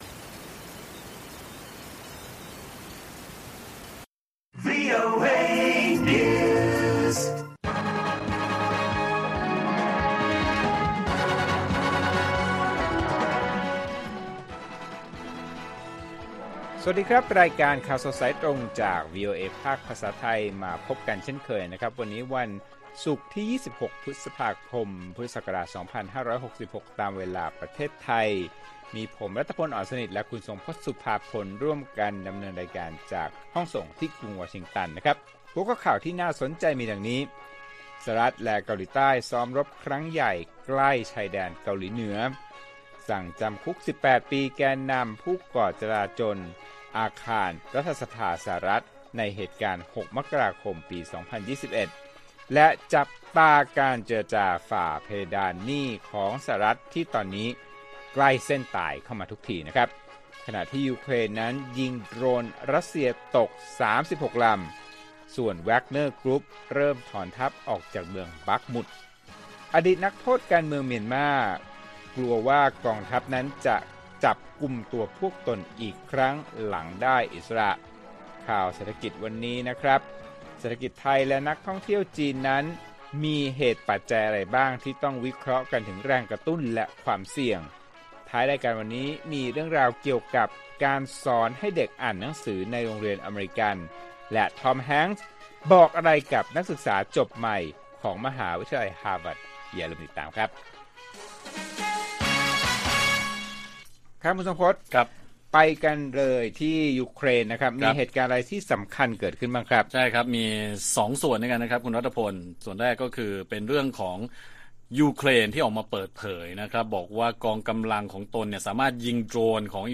ข่าวสดสายตรงจากวีโอเอไทย ศุกร์ ที่ 26 พ.ค. 66